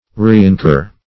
reincur - definition of reincur - synonyms, pronunciation, spelling from Free Dictionary Search Result for " reincur" : The Collaborative International Dictionary of English v.0.48: Reincur \Re`in*cur"\ (-k?r"), v. t. To incur again.